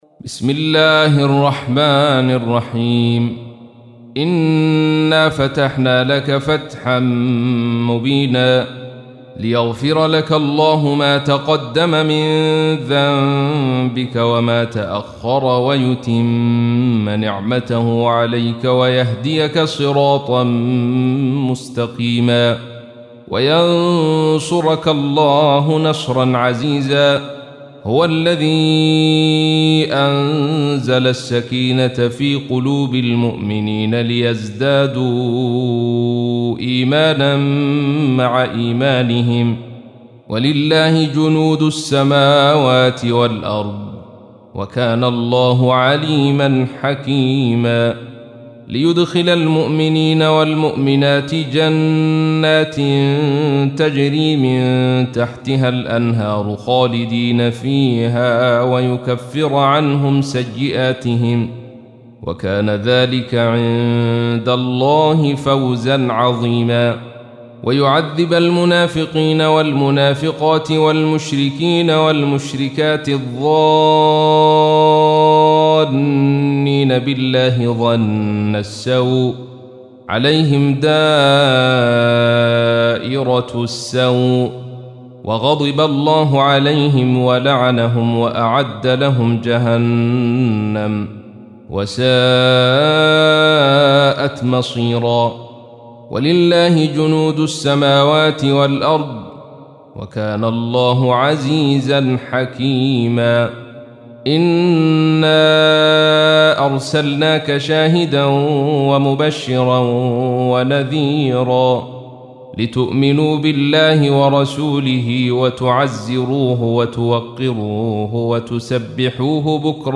تحميل : 48. سورة الفتح / القارئ عبد الرشيد صوفي / القرآن الكريم / موقع يا حسين